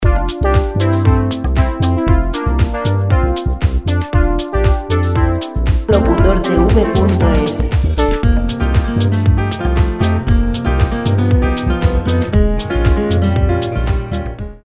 Música  pop libre de derechos de autor.